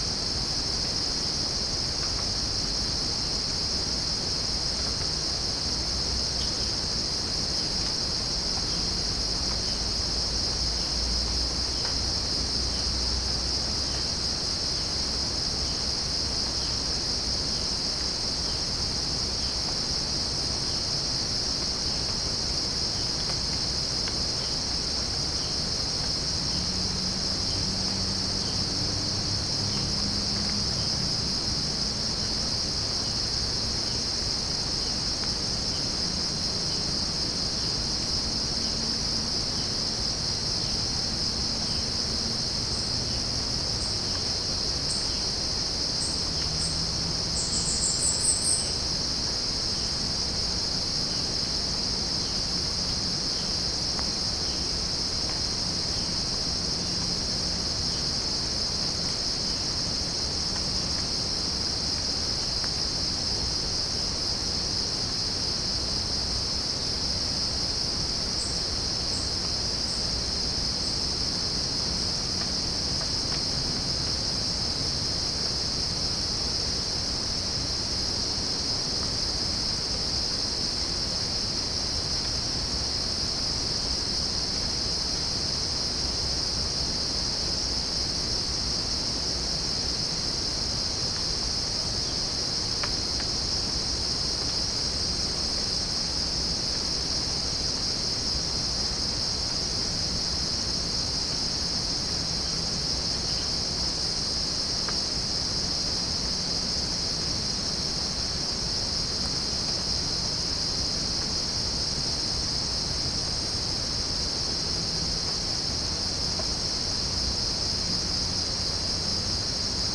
Chalcophaps indica
Pycnonotus goiavier
Halcyon smyrnensis
Pycnonotus aurigaster
Orthotomus ruficeps
Dicaeum trigonostigma